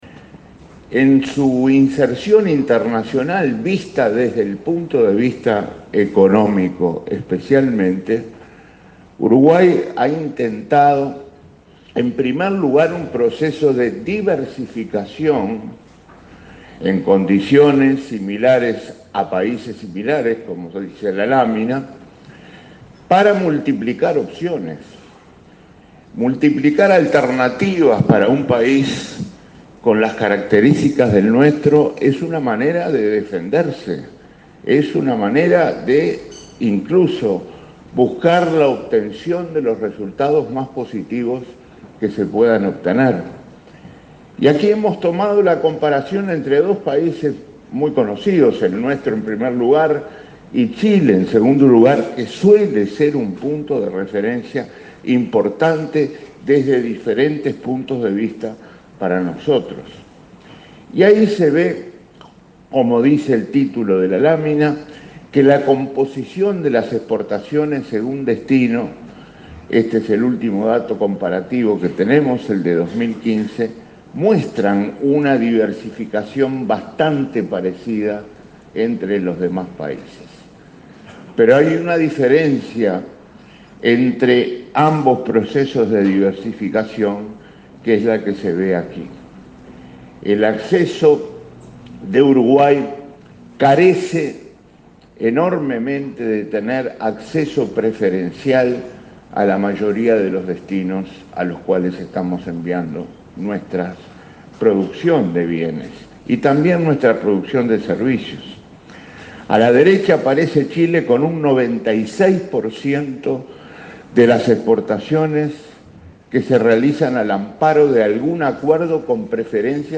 La economía uruguaya sigue diferenciándose de la de los países vecinos, aseguró este martes el ministro Danilo Astori en un foro de ACDE. El jerarca proyectó un crecimiento acumulado de 21 % para el período 2011-2018, frente a 5 % de Argentina y 2 % de Brasil.